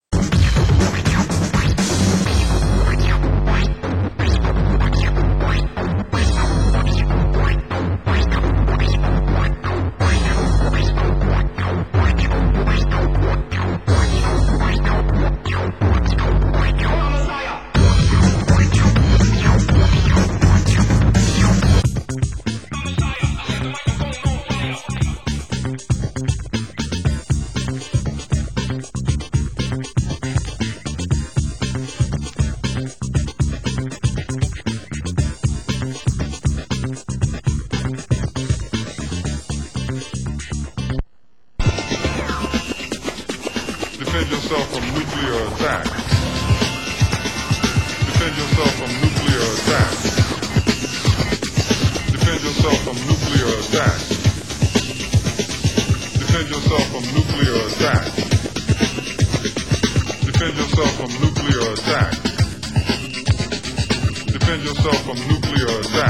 Genre Break Beat